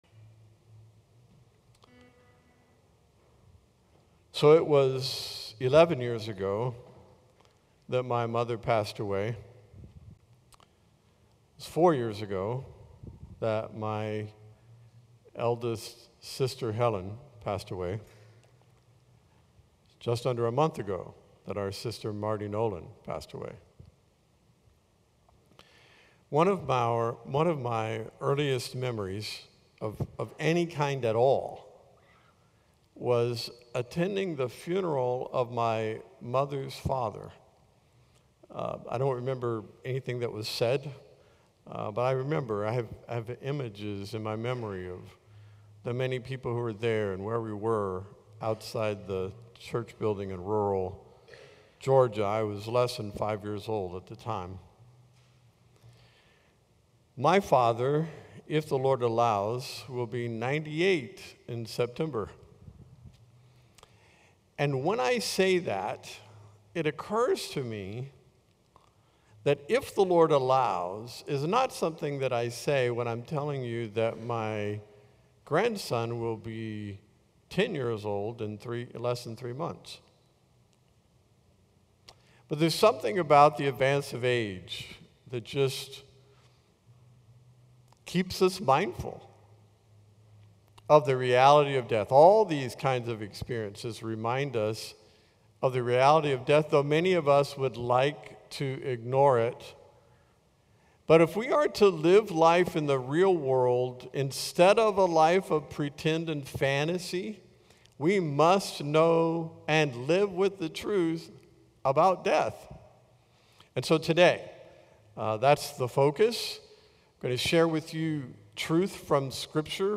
A message from the series "The Truth About ...."